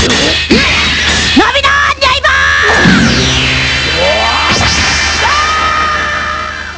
ATTACK
Este es un ataque de algun personaje, de alguna de las tres series.